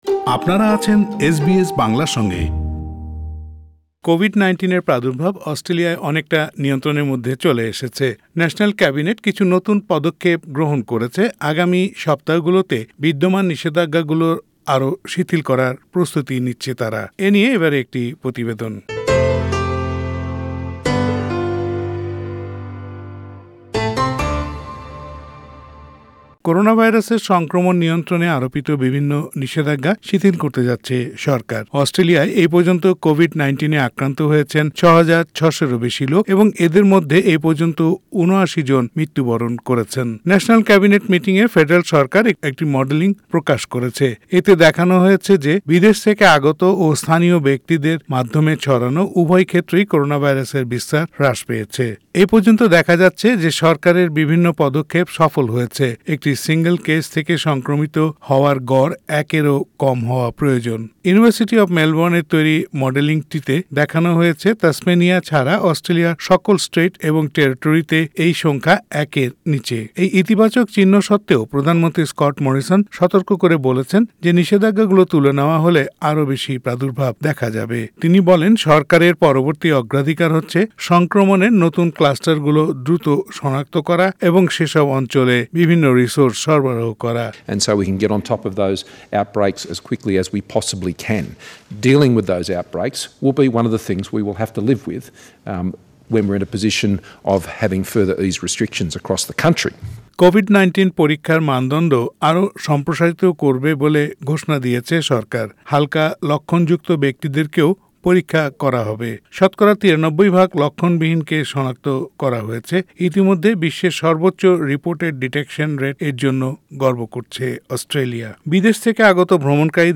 আগামী সপ্তাহগুলোতে বিদ্যমান নিষেধাজ্ঞাগুলো আরও শিথিল করার প্রস্তুতি নিচ্ছে ফেডারেল সরকার। প্রতিবেদনটি শুনতে উপরের অডিও প্লেয়ারের লিংকটিতে ক্লিক করুন।